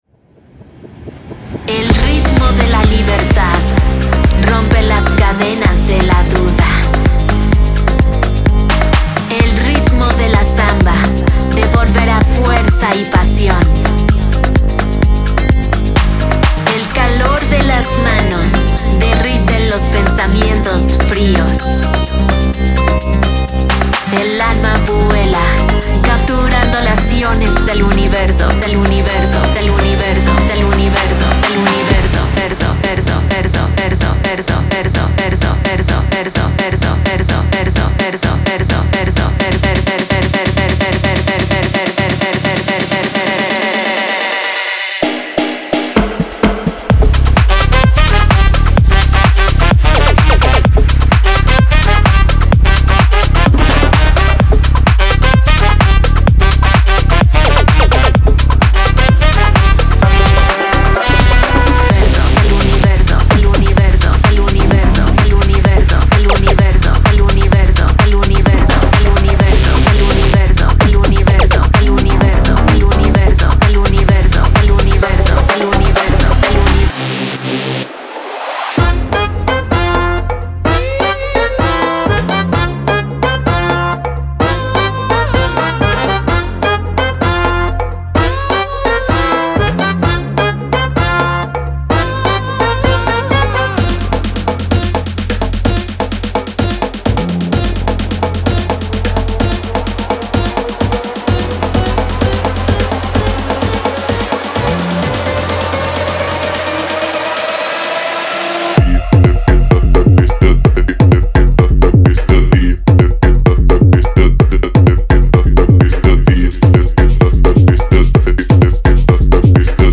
Genre:Tech House